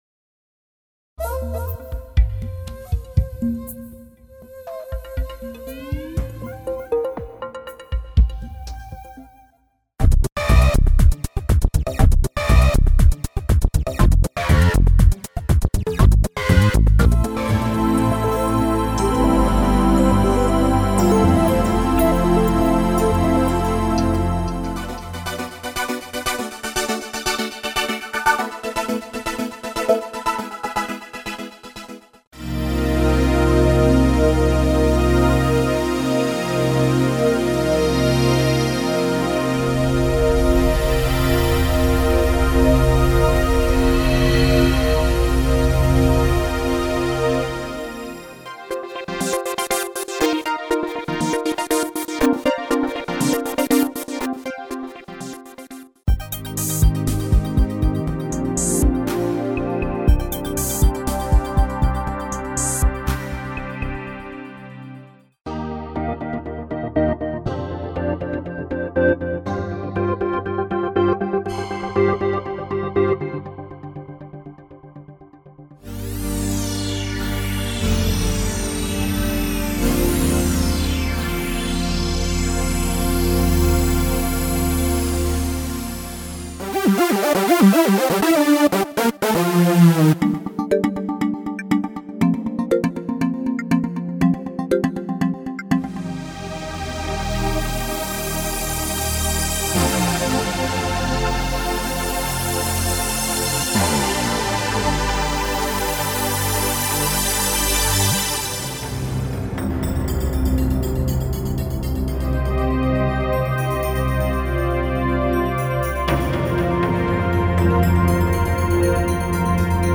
Multisample-based, fully-featured synthesizer engine
Xphraze Preset Preview